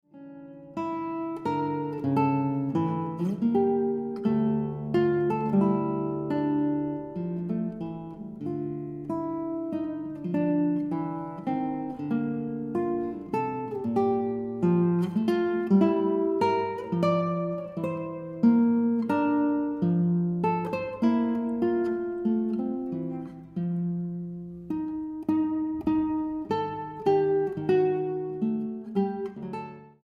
guitarra.
Sostenuto